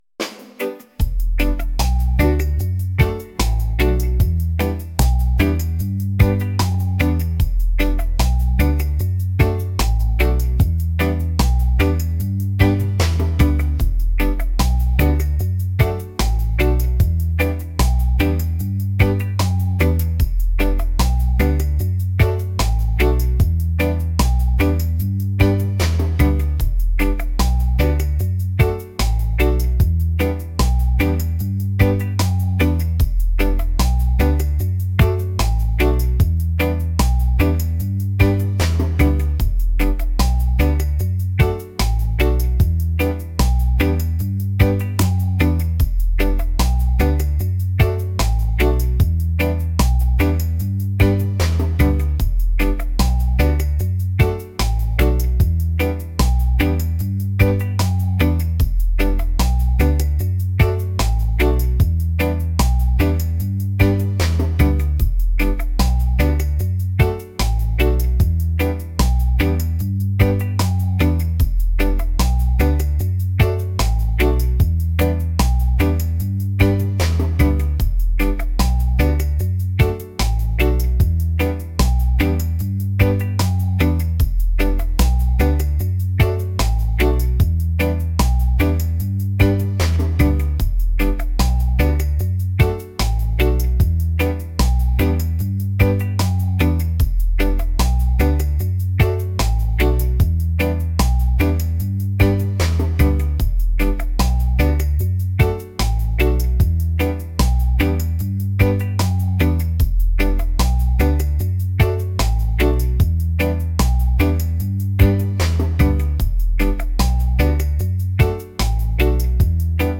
reggae | laid-back | smooth